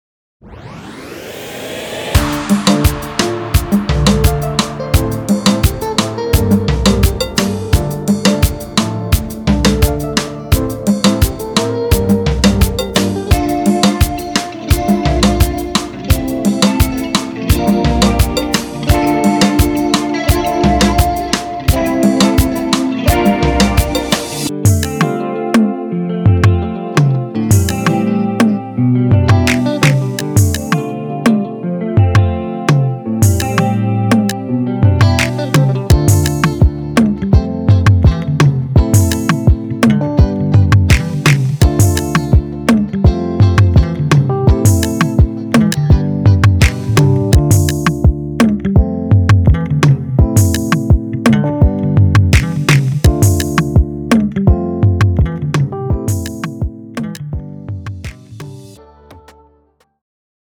Niche